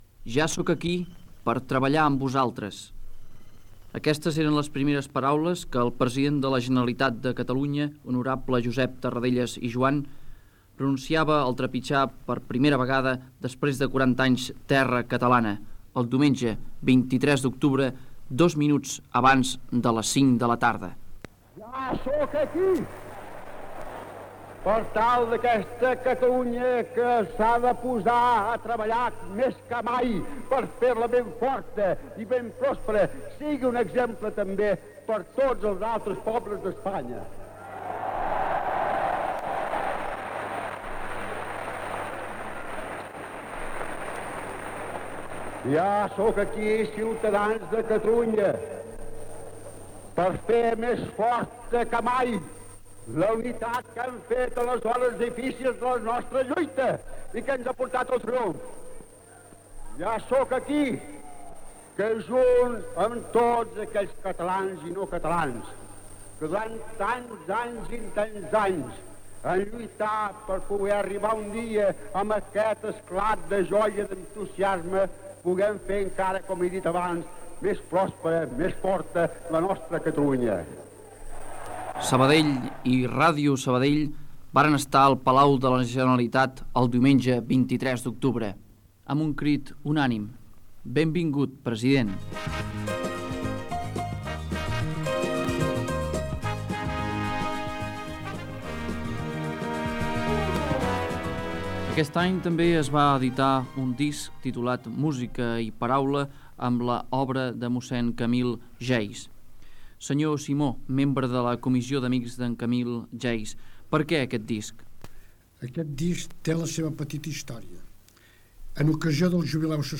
Resum informatiu de l'any a la ciutat de Sabadell. Discursos de cap d'any i Nadal del president Josep Tarradellas i el rei Juan Carlos I.